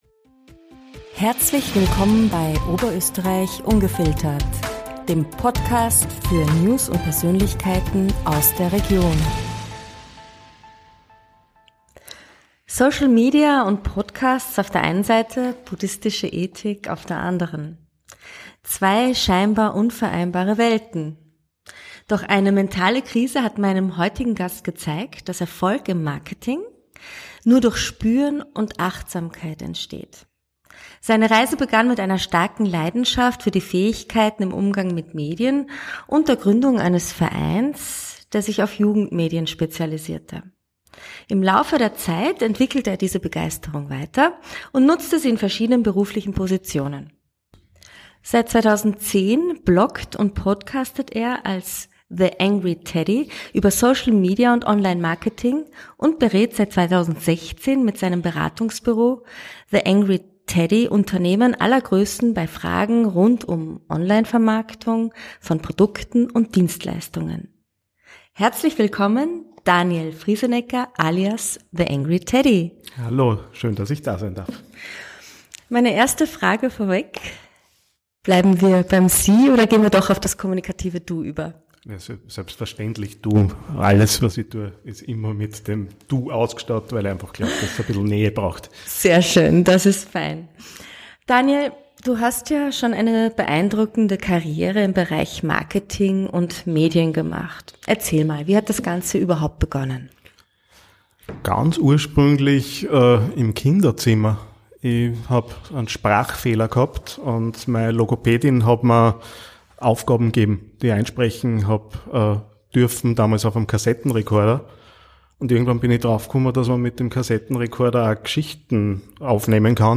Podcast-Talk